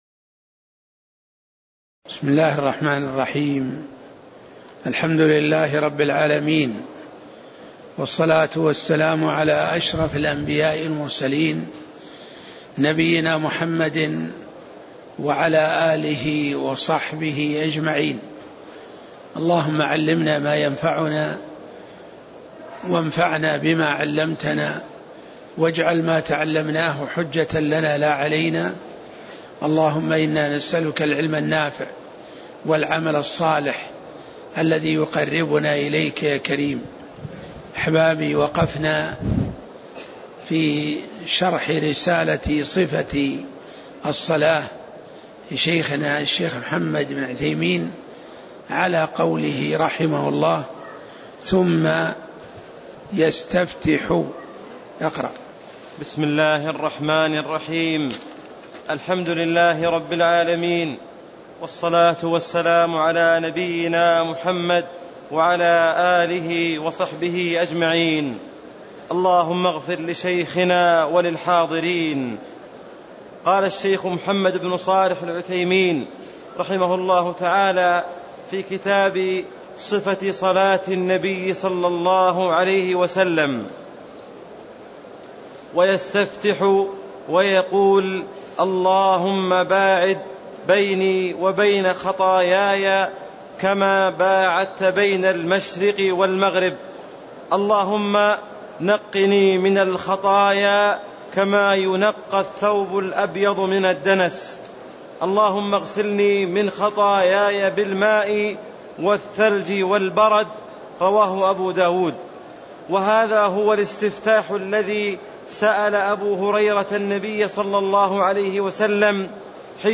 تاريخ النشر ١٣ شوال ١٤٣٦ هـ المكان: المسجد النبوي الشيخ